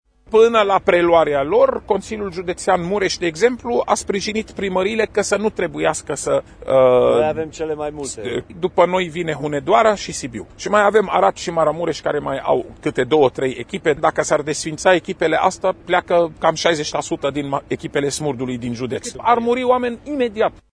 Secretarul de stat din cadrul MAI, Raed Arafat, a evidențiat exemplul pozitiv al județului Mureș, unde Consiliul Județean a decis să le finanțeze: